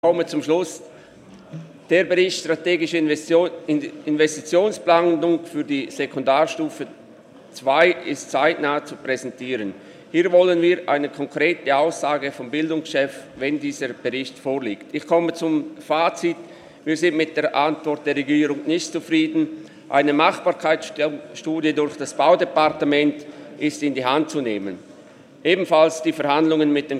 25.11.2019Wortmeldung
Sprecher: Bühler-Bad Ragaz
Session des Kantonsrates vom 25. bis 27. November 2019